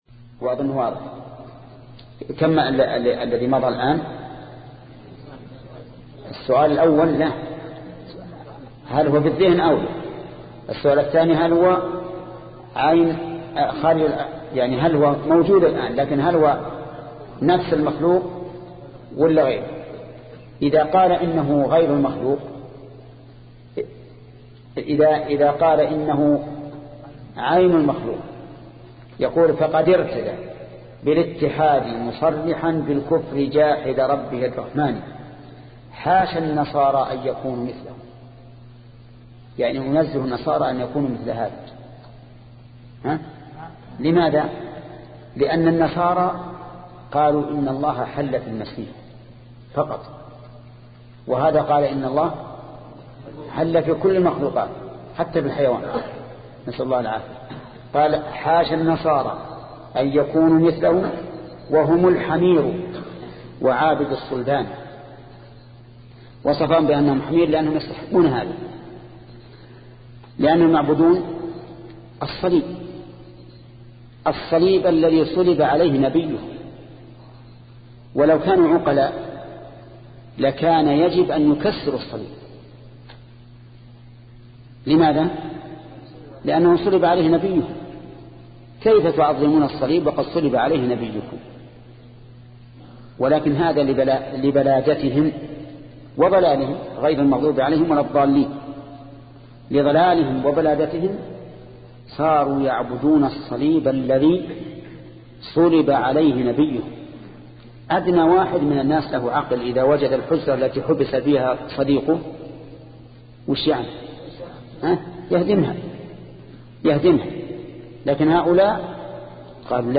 شبكة المعرفة الإسلامية | الدروس | التعليق على القصيدة النونية 14 |محمد بن صالح العثيمين